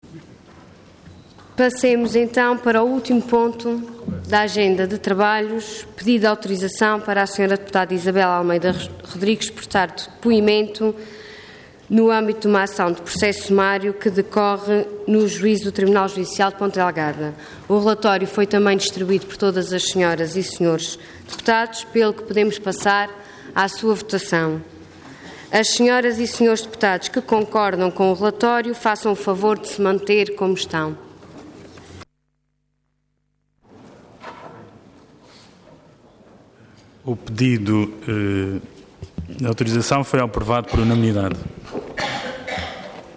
Assembleia Legislativa da Região Autónoma dos Açores
Intervenção
Ana Luísa Luís
Presidente da Assembleia Regional